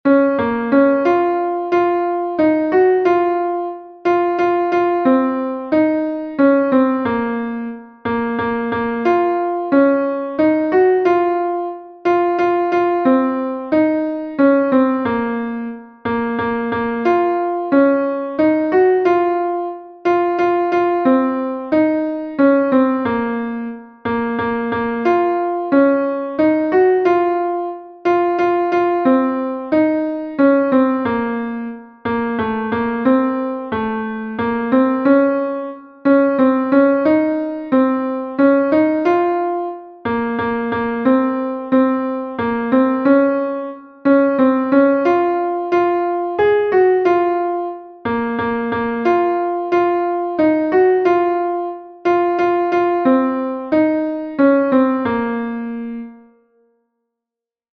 8 / 8A / 8 / 8A / 8 / 8A / 8 / 8A / 8 / 8A / 8 / 8A / 8 / 8A